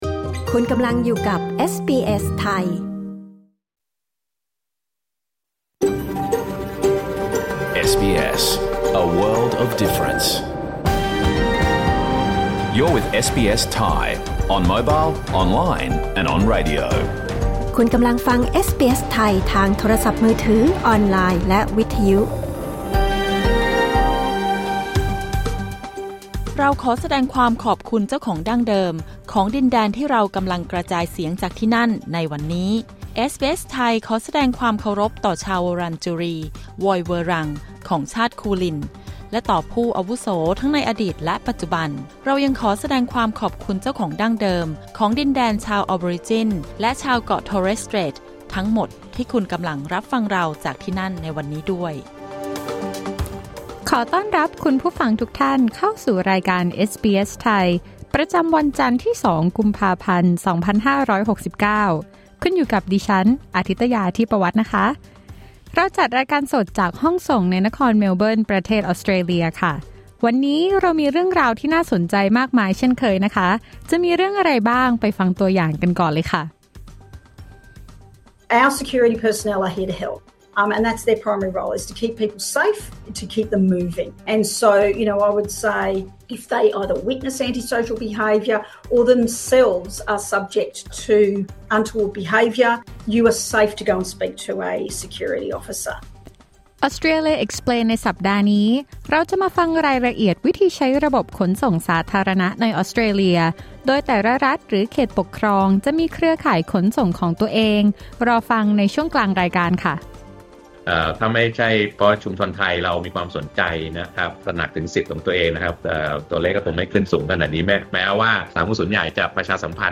รายการสด 2 กุมภาพันธ์ 2569